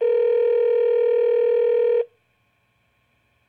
ring.ogg